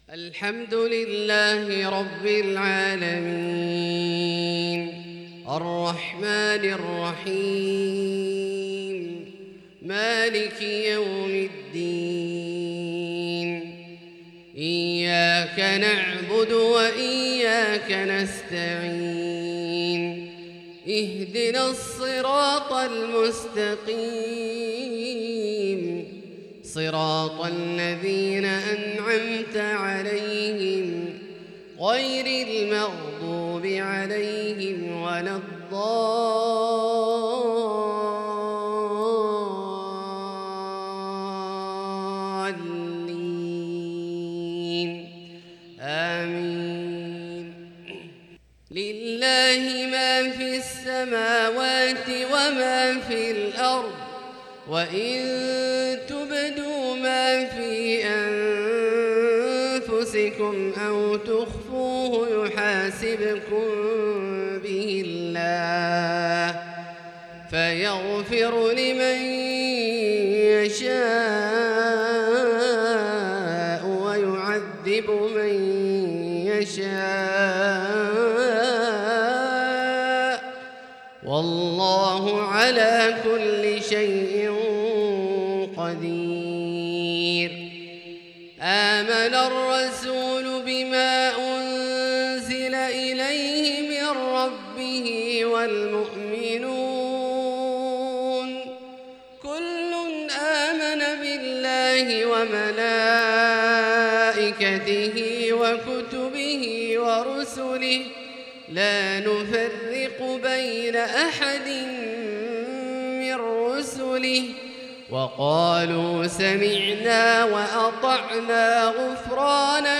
تلاوة فريدة رائعة لخواتيم سورتي البقرة وآل عمران للشيخ عبدالله الجهني | عشاء 10 جمادى الآخرة 1444هـ